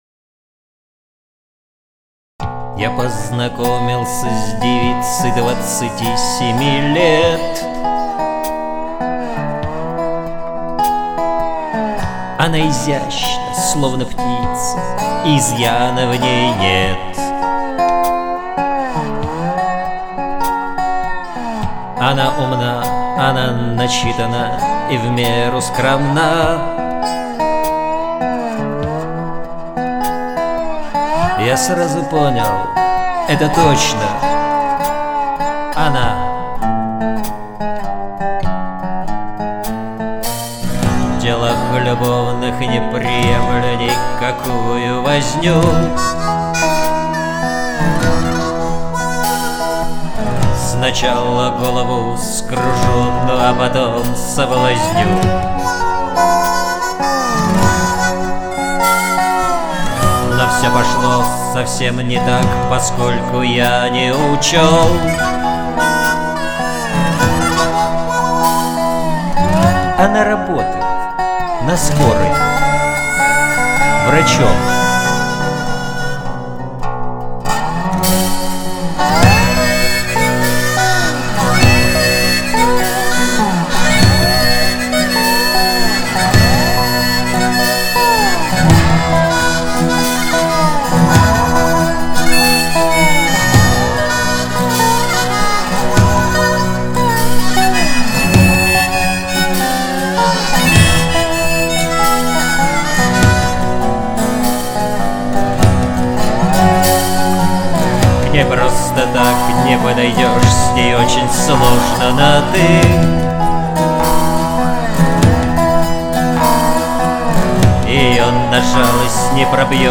песни скорой помощи